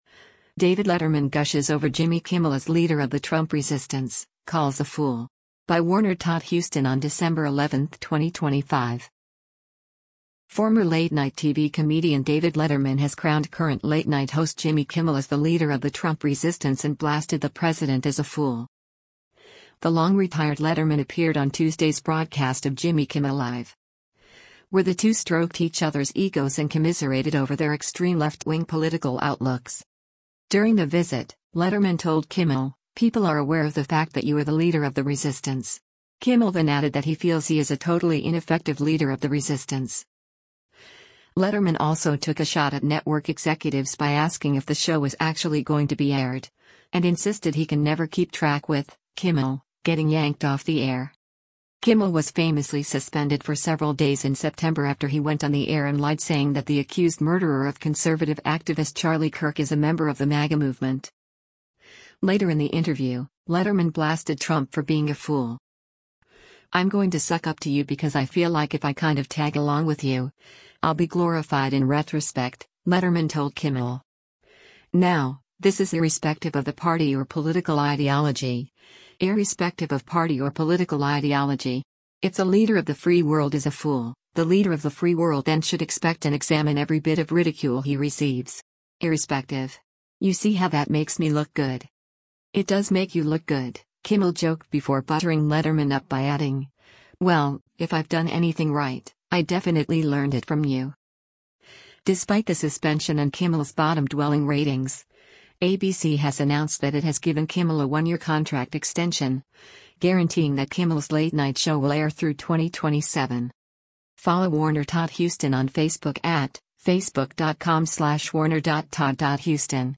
The long-retired Letterman appeared on Tuesday’s broadcast of Jimmy Kimmel Live! where the two stroked each other’s egos and commiserated over their extreme left-wing political outlooks.
Later in the interview, Letterman blasted Trump for being a fool.